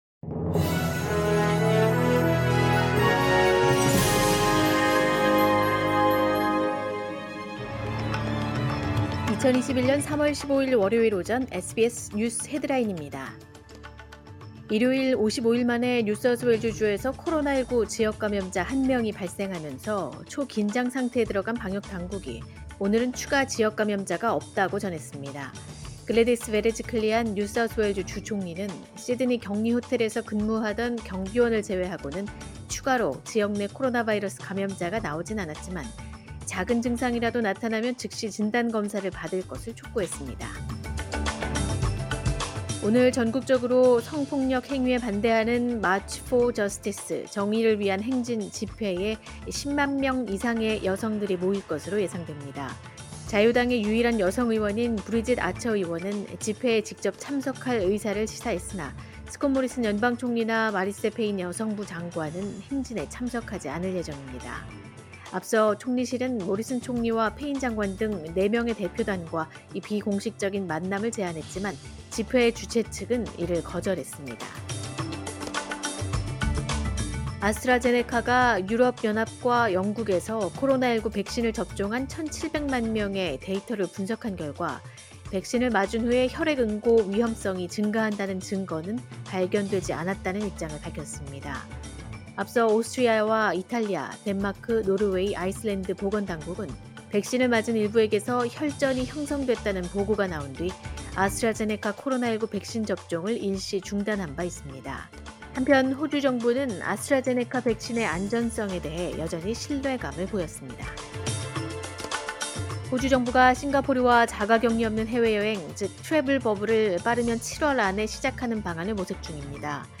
2021년 3월 15일 월요일 오전의 SBS 뉴스 헤드라인입니다.